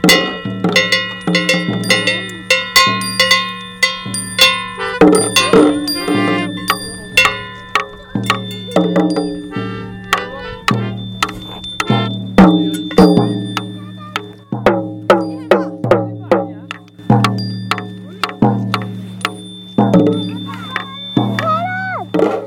합주.mp3